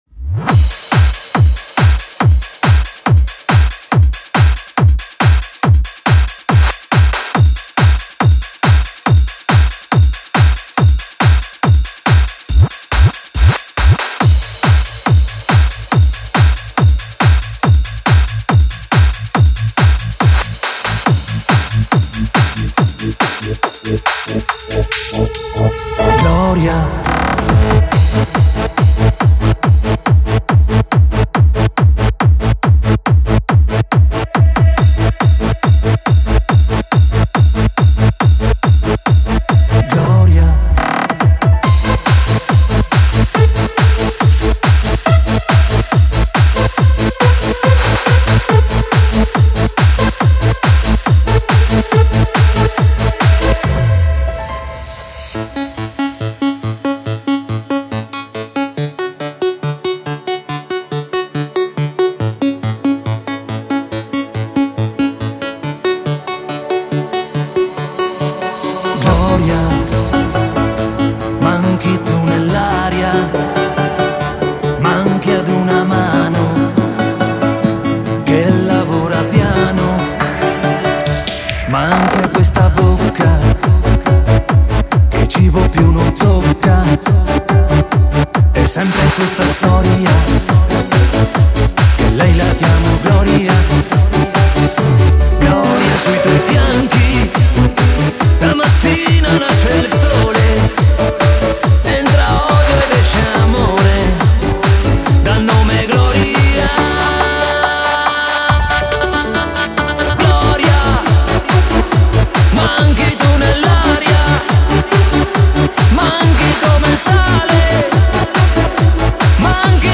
Italo Dance
Hard Dance Style